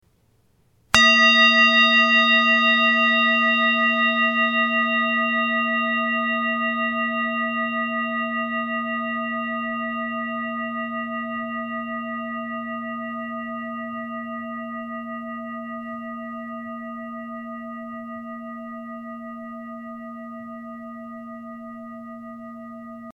Lieferumfang: Klangschale, Klöppel
ALTE TIBETISCHE KLANGSCHALE - MOND METONISCHER ZYKLUS + ERDE HOPI HERZTON
Grundton: 231,50 Hz
1. Oberton: 651,52 Hz